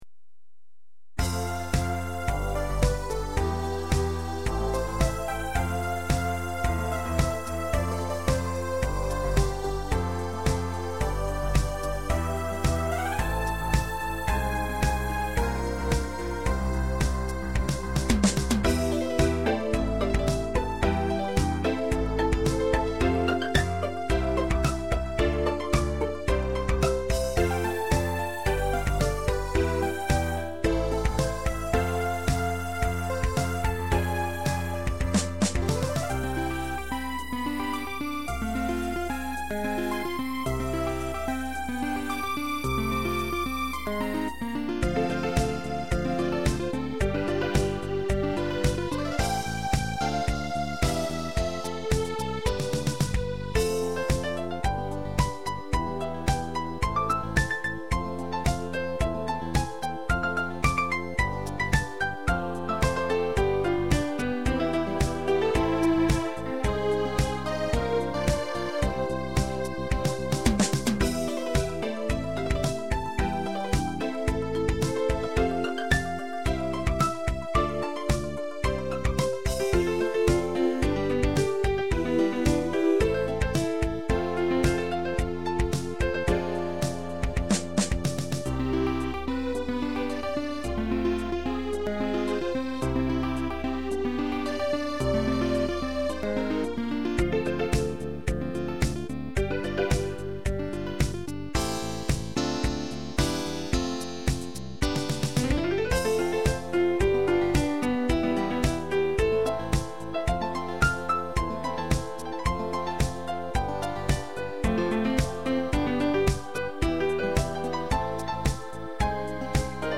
アンサンブル